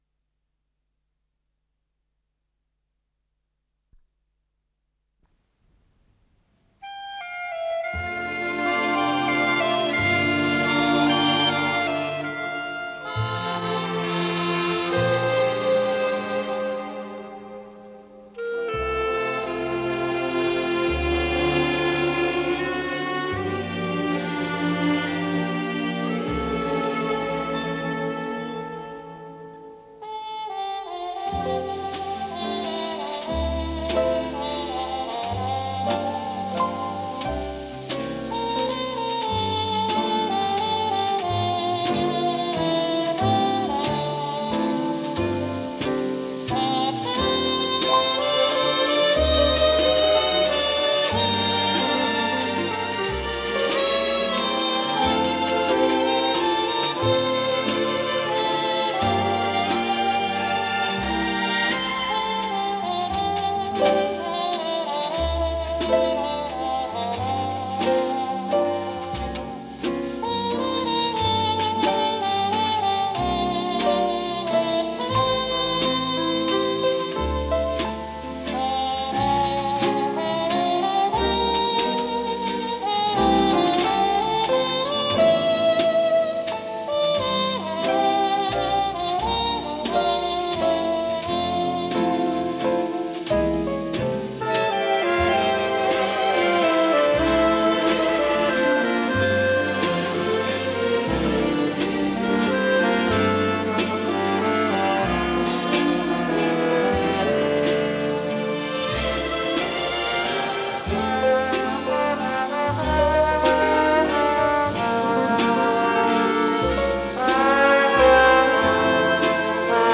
с профессиональной магнитной ленты
труба
ВариантДубль моно